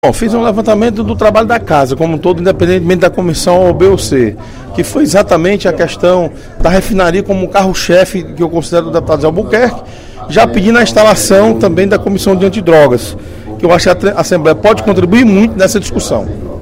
No primeiro expediente da sessão plenária desta quinta-feira (19/12), o deputado Osmar Baquit (PSD) fez um balanço das atividades desenvolvidas pelo Poder Legislativo, destacando a campanha pela instalação da Refinaria e a criação de uma comissão antidrogas.